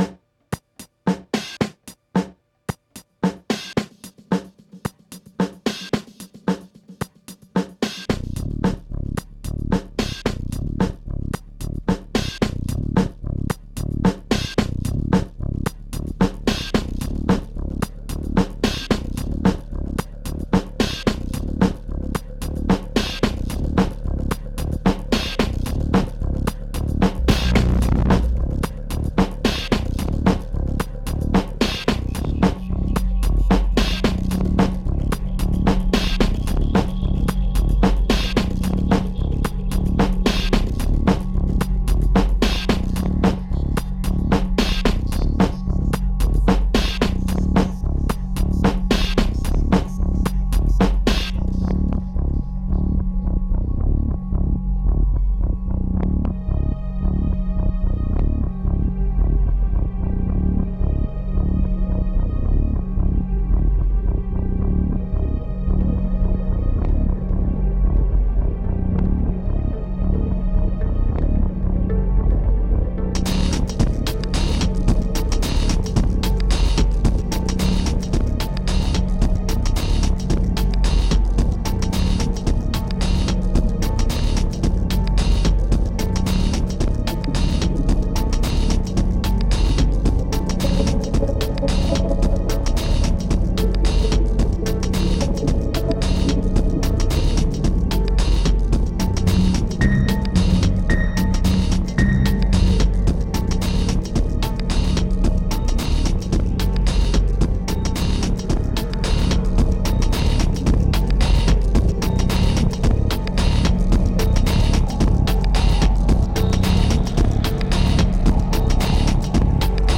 Pure remote desire, strong medication and raw electronica.
2455📈 - 11%🤔 - 111BPM🔊 - 2011-01-13📅 - -367🌟